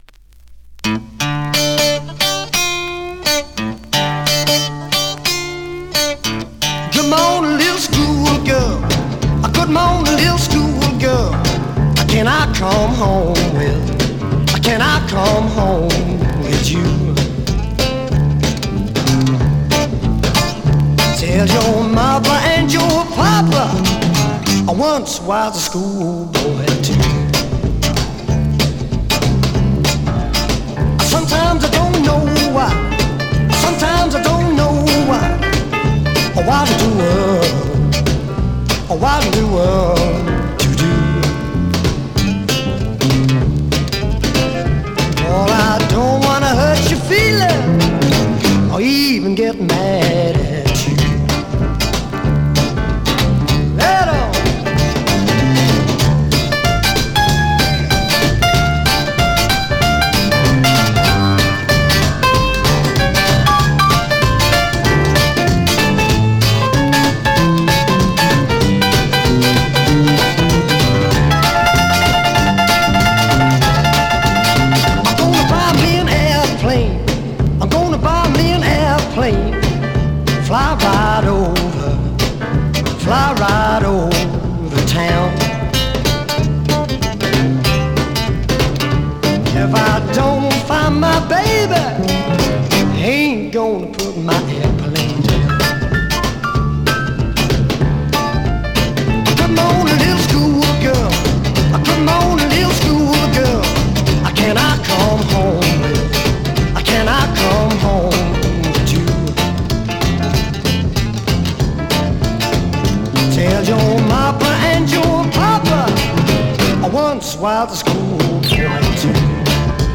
Vinyl has a few light marks plays great .
R&B, MOD, POPCORN